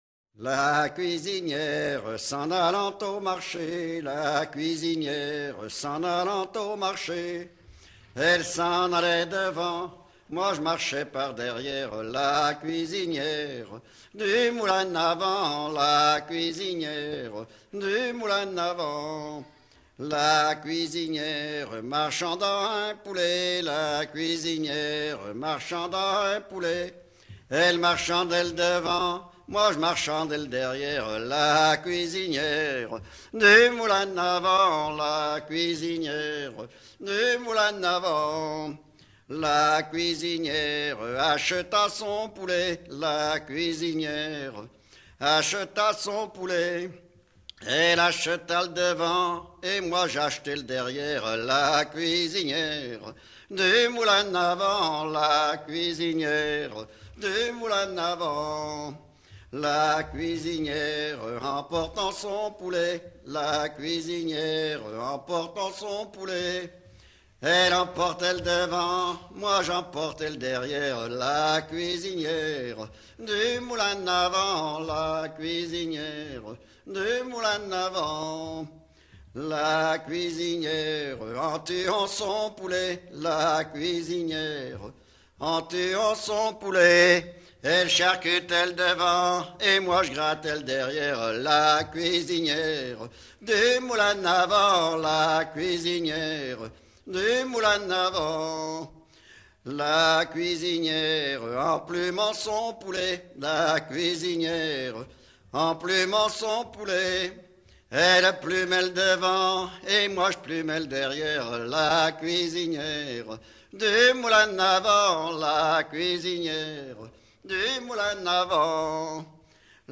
Boufféré ( Plus d'informations sur Wikipedia ) Vendée
Genre énumérative
Pièce musicale éditée